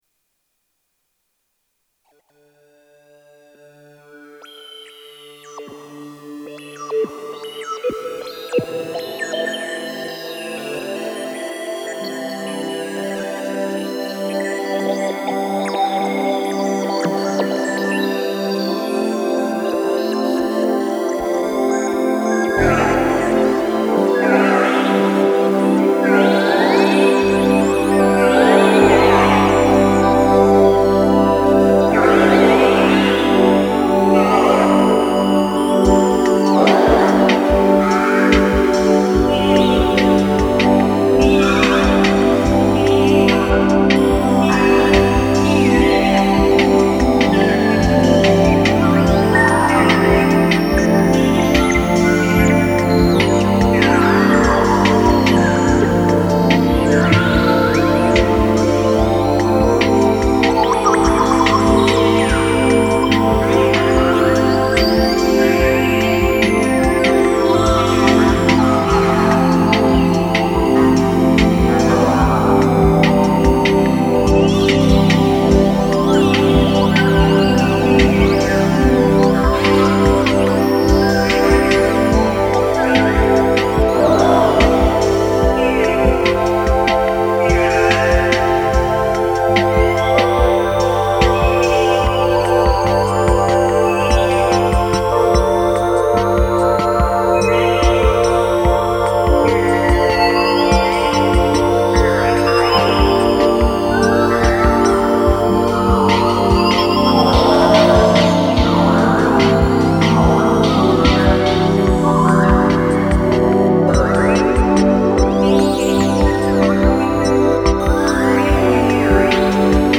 Genre: Ambient